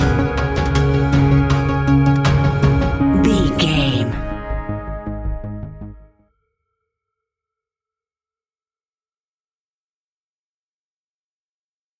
Aeolian/Minor
A♭
ominous
dark
haunting
eerie
synthesizer
drum machine
horror music
Horror Pads